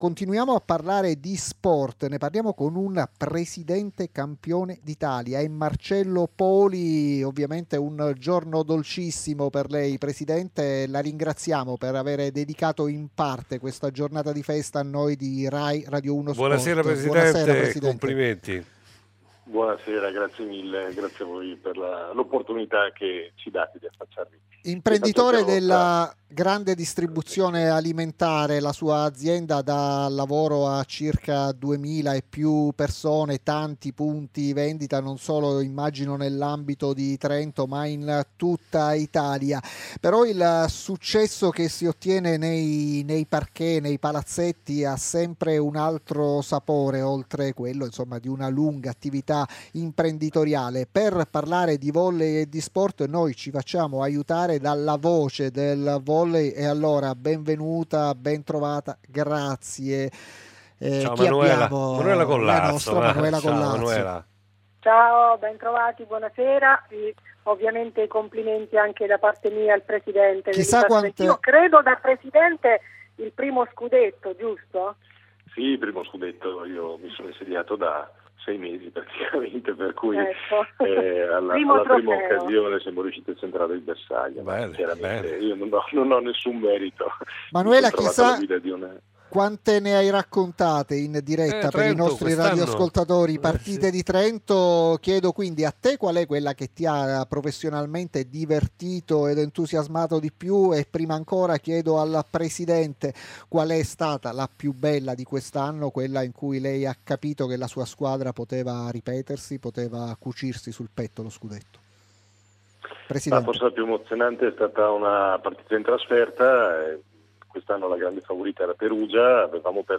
L'intervista integrale Trentino Volley Srl Ufficio Stampa